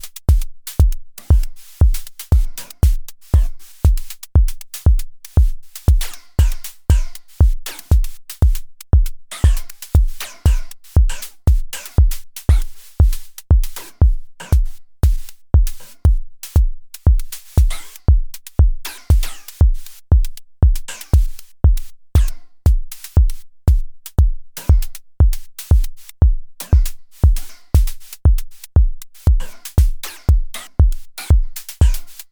Some silly pseudo generative drums.